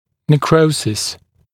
[nek’rəusɪs][нэк’роусис]некроз, омертвение, отмирание